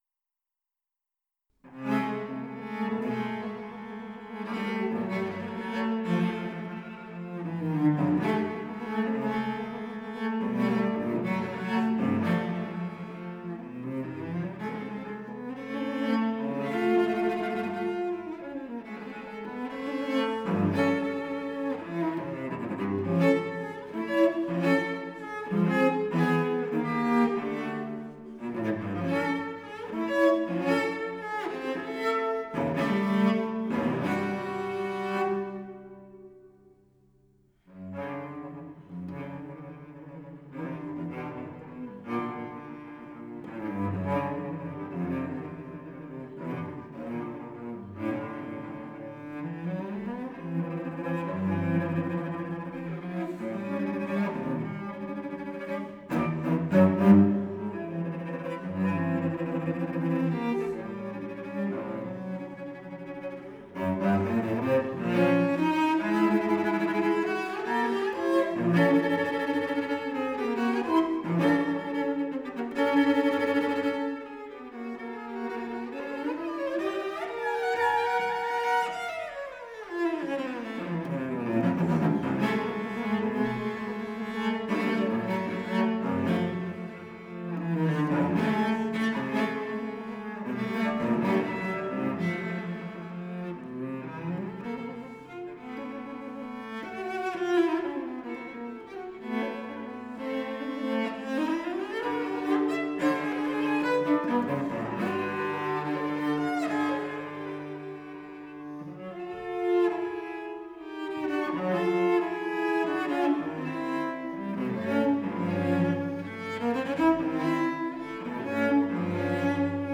Live Recordings / Live Aufnahmen
World Premiere, Festival du Jura, Porrentruy